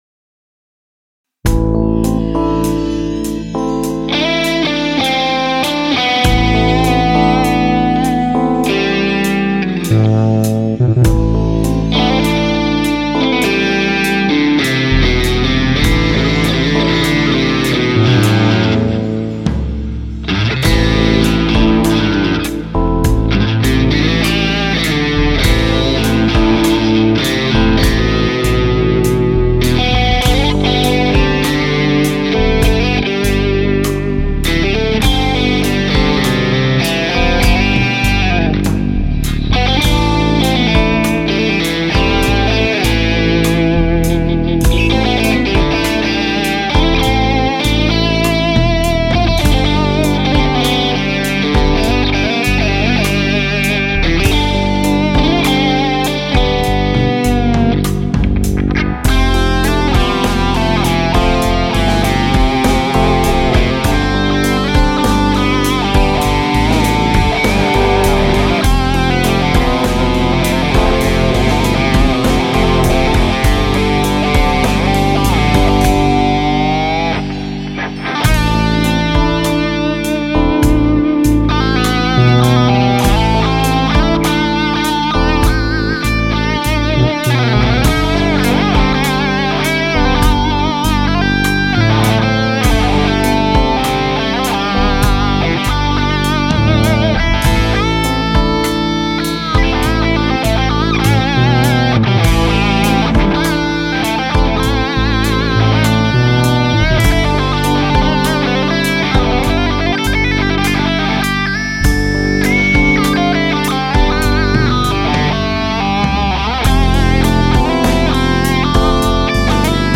Das ist meine Suhr, der Amp ist Axe.. was sonst. Und zwar dieses Marshall-Ding JM45